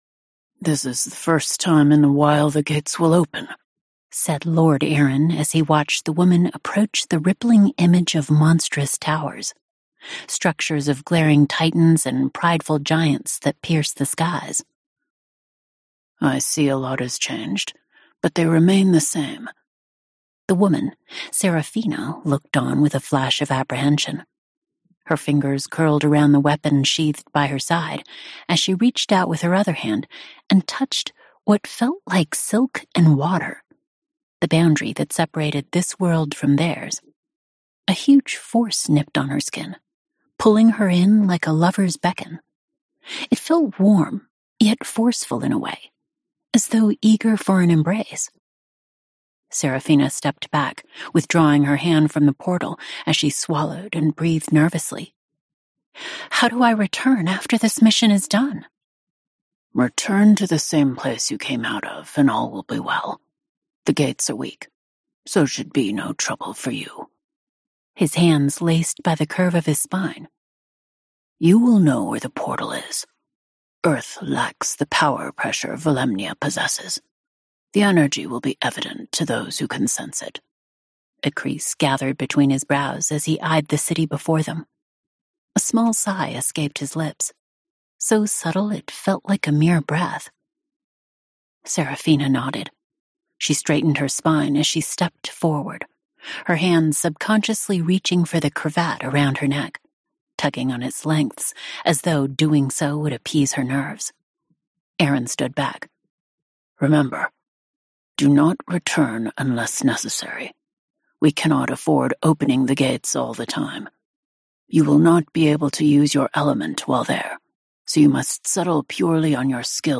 Celeste Academy Unabridged Audiobook Categories